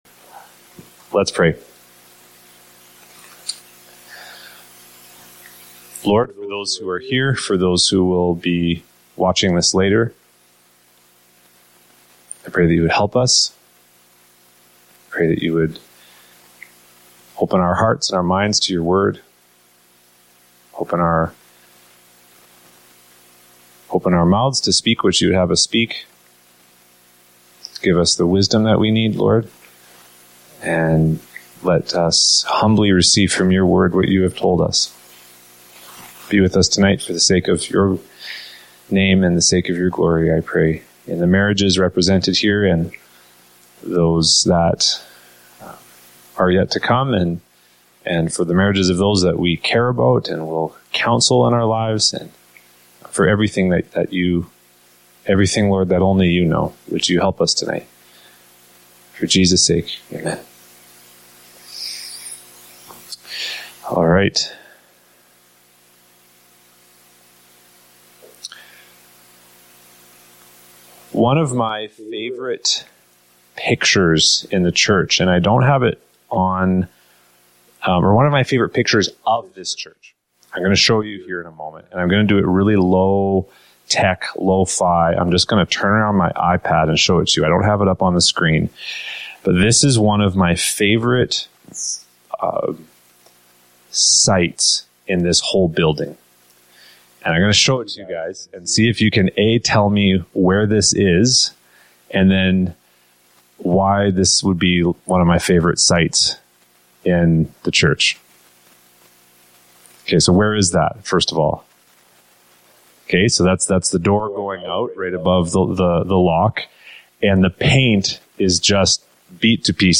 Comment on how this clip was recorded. We experienced some quality issues with the audio and video recording of this session.